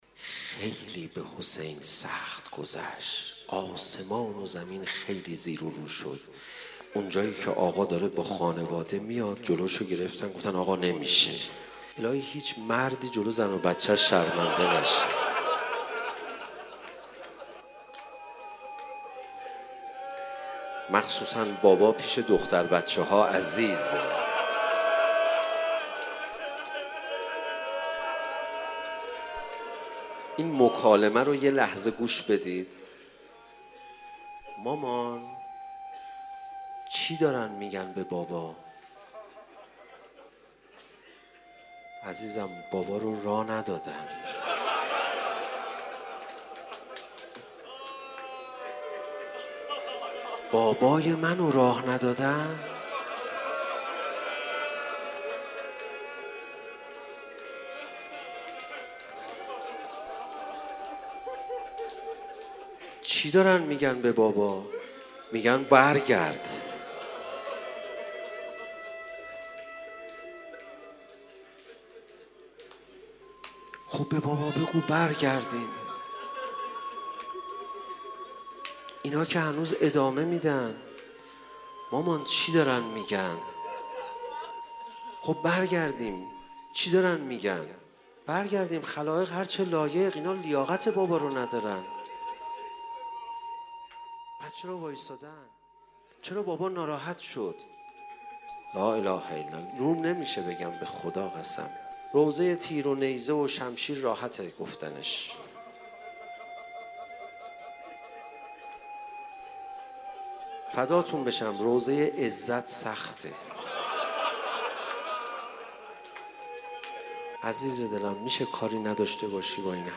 روضه
روضه حضرت رقیه